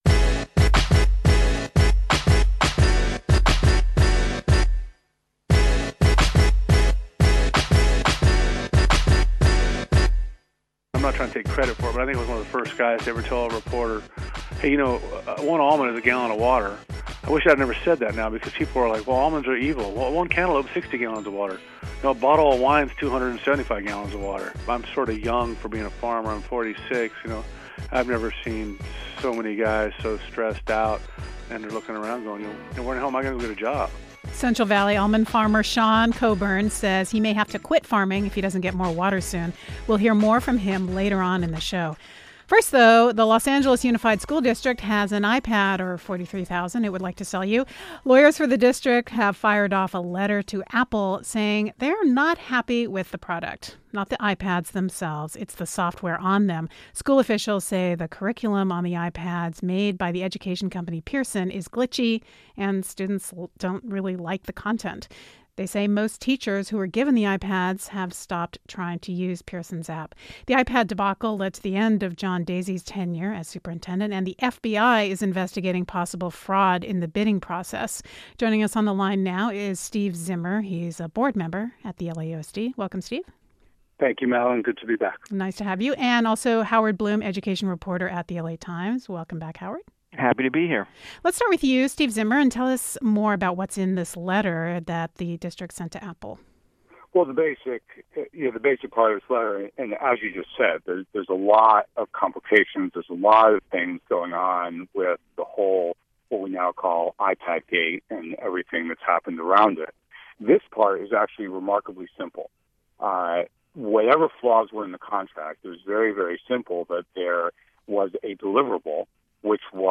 Next, we hear from two Central Valley farmers about the impacts of California’s drought and the state